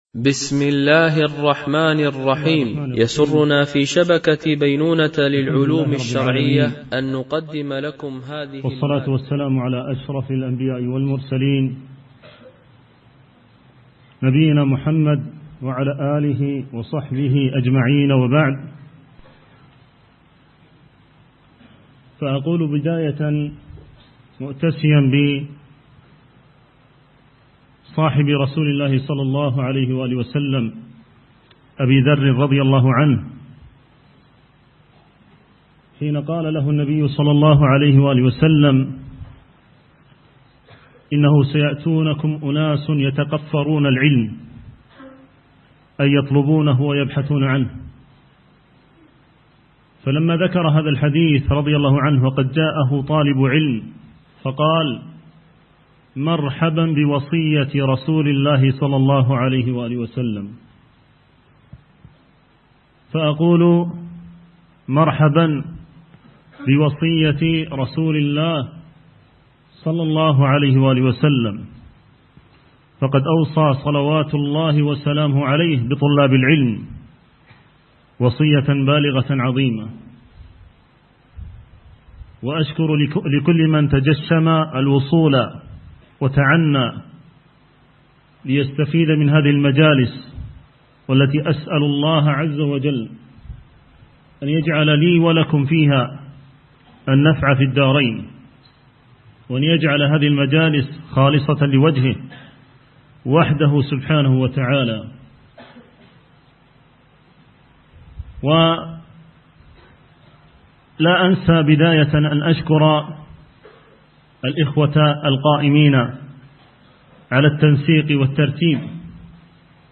شرح صفوة أصول الفقه ـ الدرس الأول